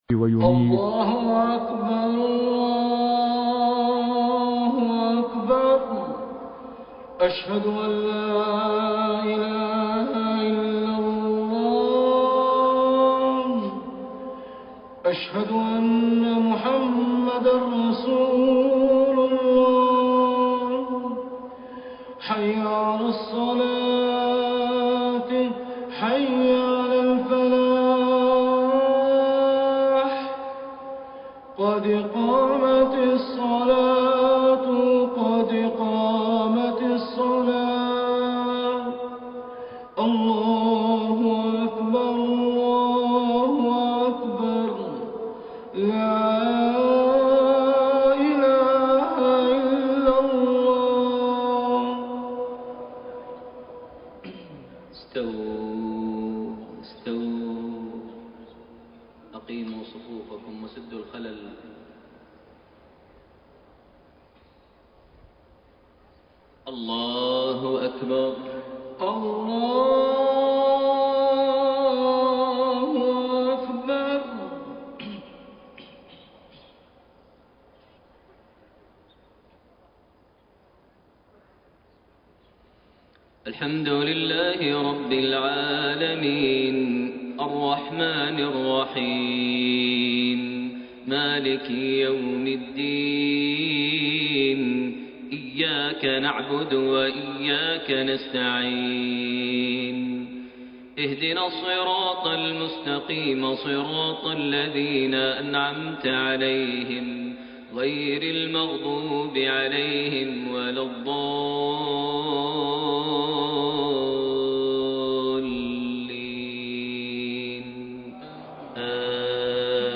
Maghrib prayer from Surah Al-Lail and Ad-Dhuhaa > 1433 H > Prayers - Maher Almuaiqly Recitations